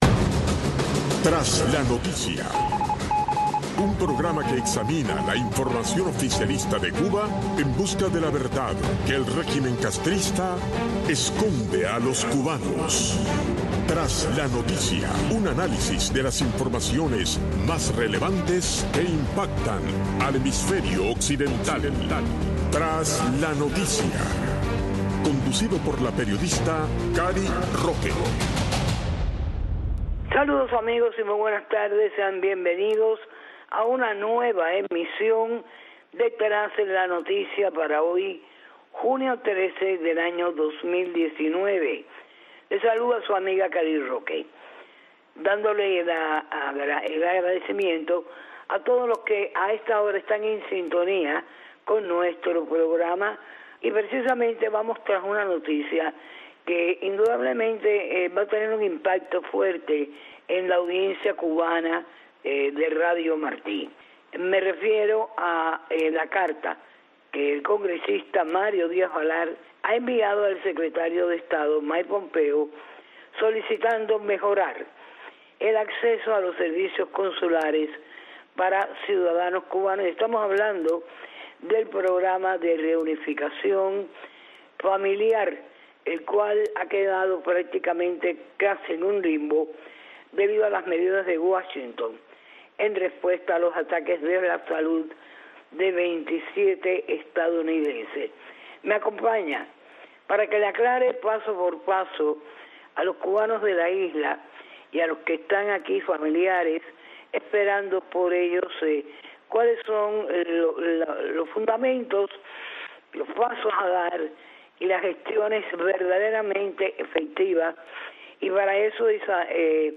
"Tras la Noticia" analiza la carta del Congresista Mario Díaz Balart solicitando al Secretario de estado Mike Pompeo mejorar el acceso a los servicios consulares para ciudadanos cubanos. Conversamos con la destacada abogada de Inmigración